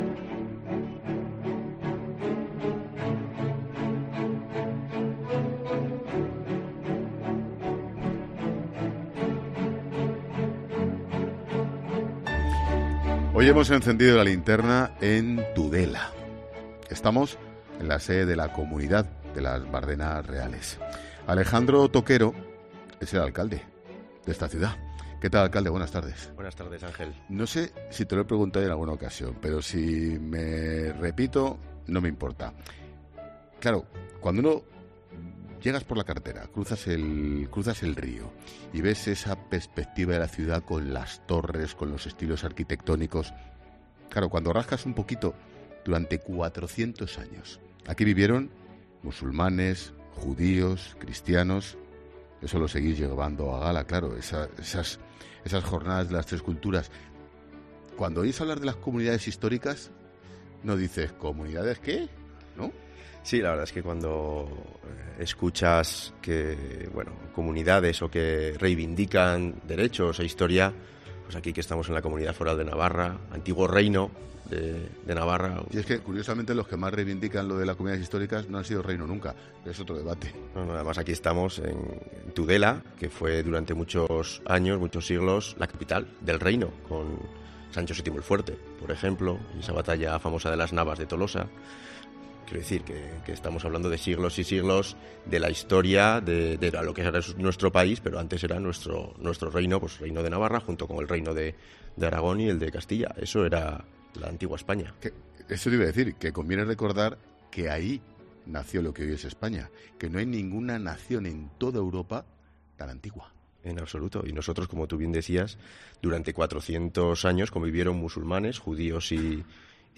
Ángel Expósito encendió La Linterna desde las Bardenas Reales y entrevistó a Alejandro Toquero
Entrevista al alcalde de Tudela, Alejandro Toquero.